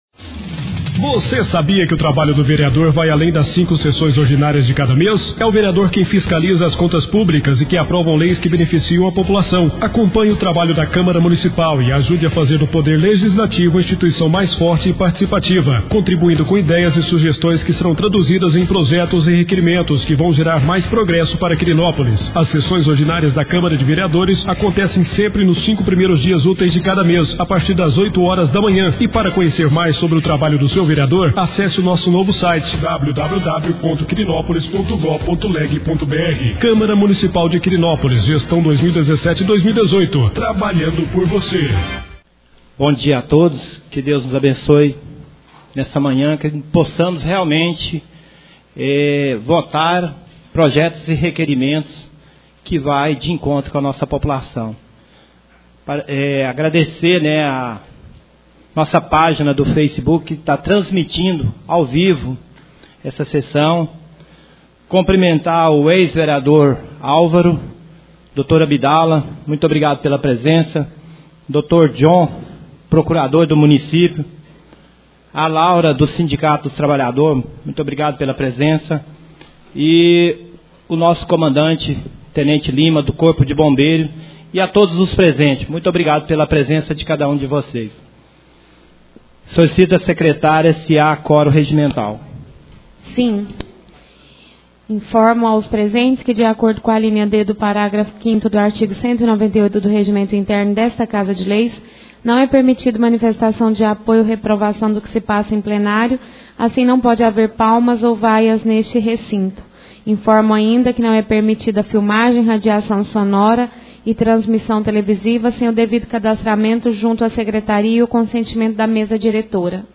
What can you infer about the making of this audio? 3° Sessão Ordinária do Mês de Junho 2017.